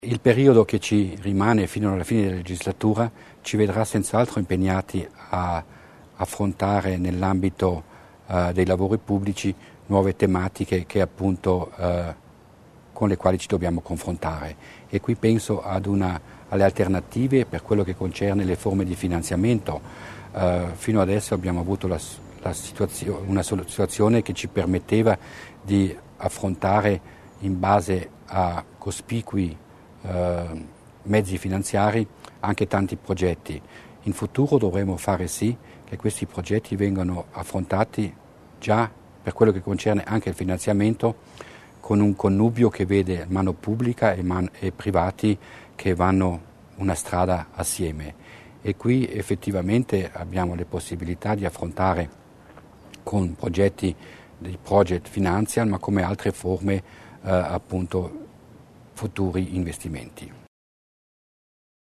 Lo ha detto oggi (4 agosto) l'assessore provinciale Florian Mussner durante il colloquio di metà legislatura.